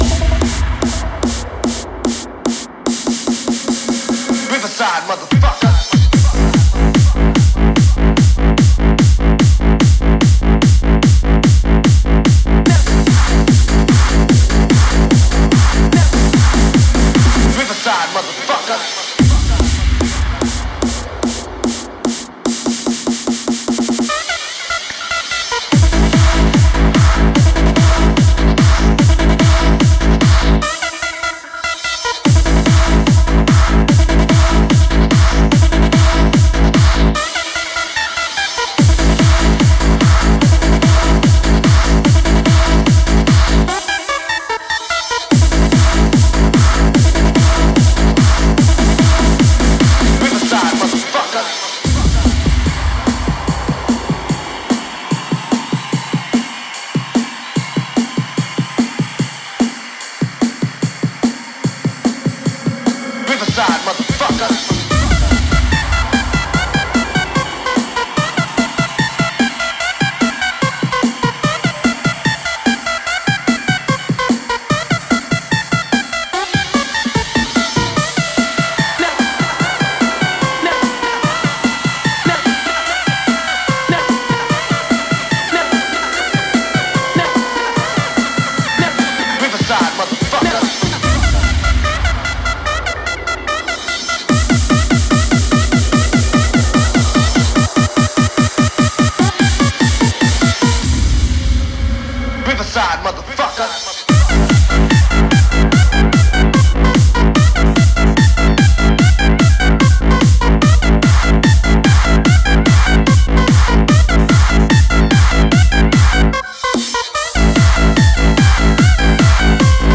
Категория: Shuffle